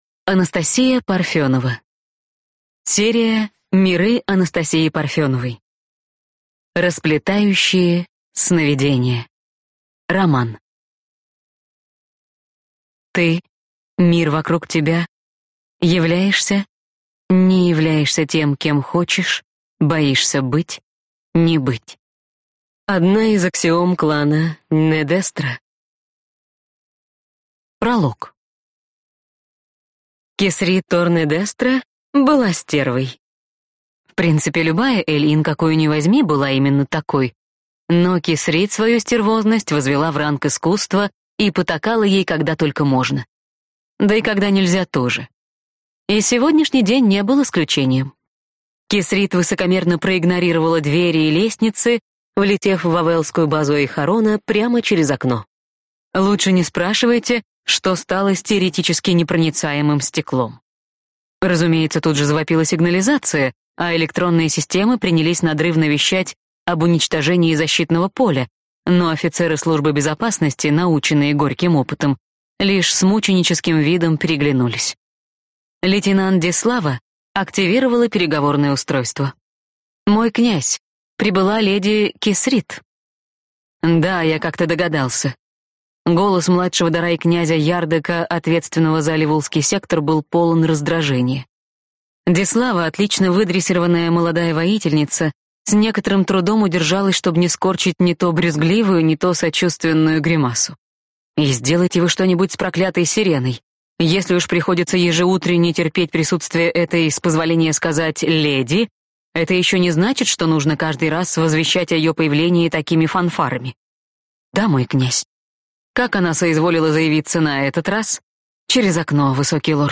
Аудиокнига Расплетающие Cновидения | Библиотека аудиокниг